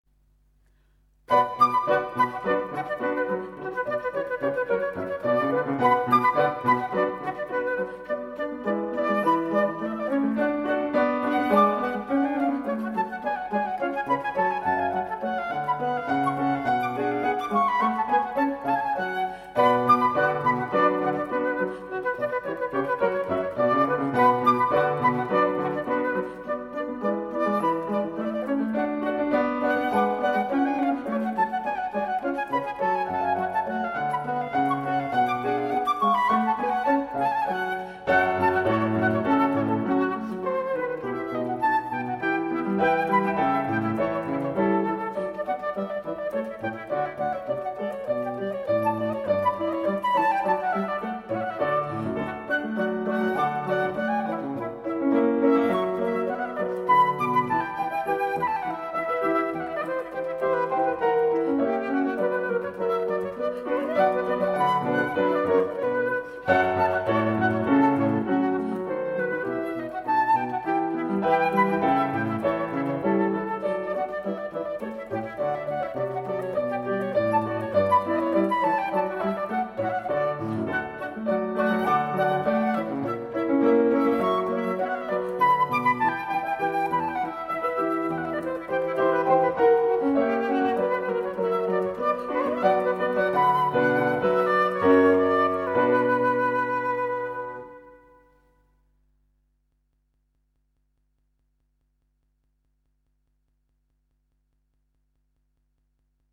小品式的音乐，长笛、钢琴和竖琴的组合
录音的音色也是赏心悦耳
它的音色柔美，金属光泽中透出一种人性化的特质
这三件乐器的录音效果则非比寻常，声部的平衡感和音质的透彻感绝可媲美世界同类制作。